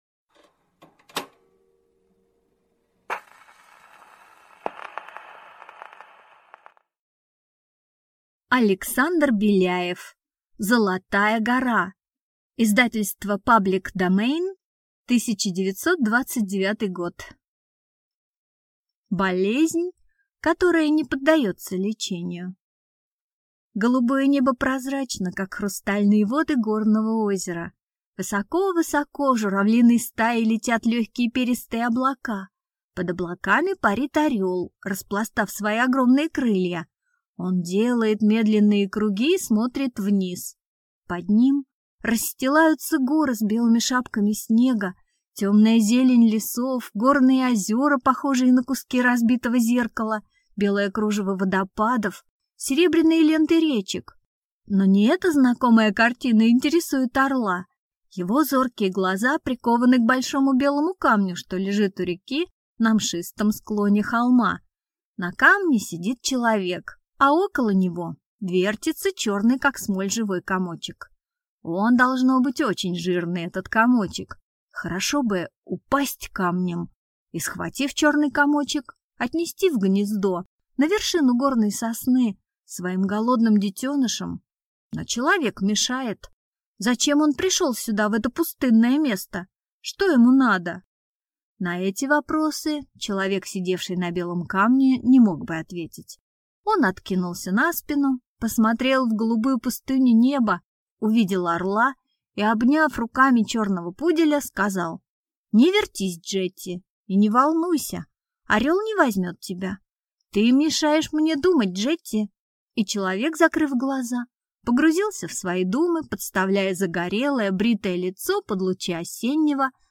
Aудиокнига Золотая гора